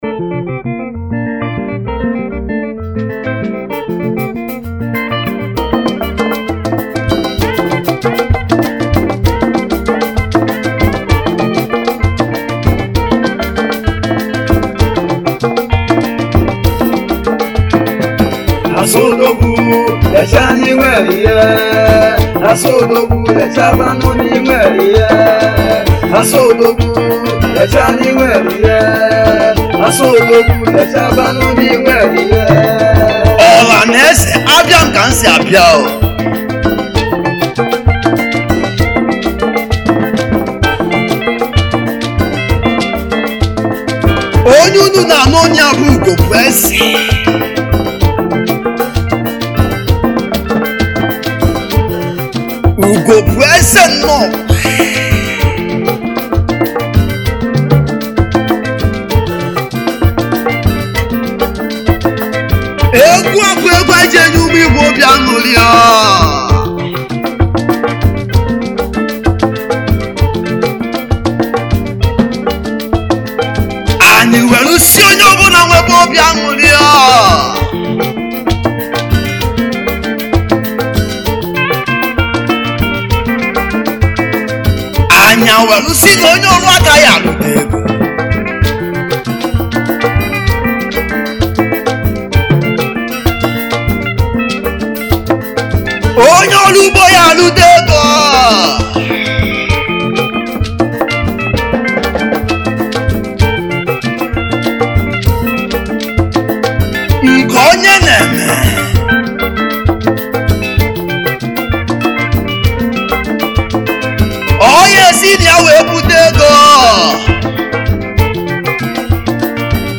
igbo highlife
highlife music band
Category : Highlife
Highlife Traditional Free